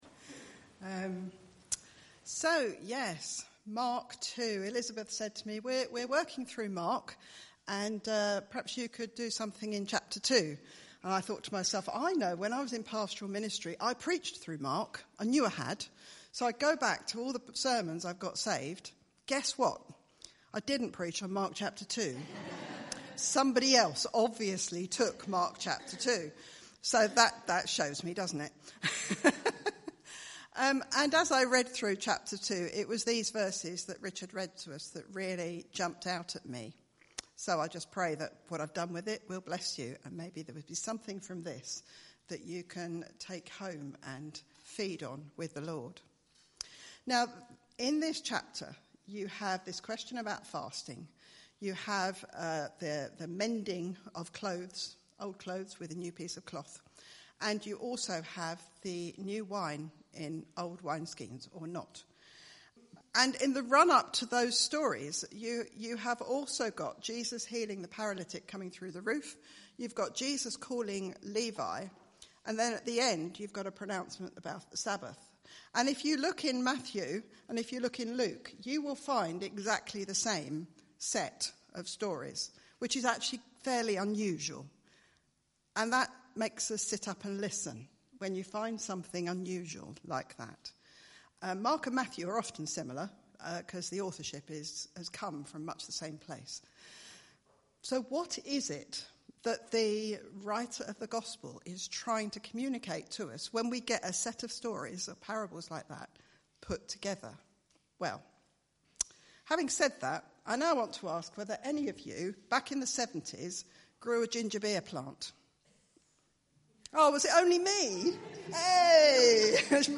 Mark 2:18-22 Service Type: Sunday Morning « Mark Chapter 1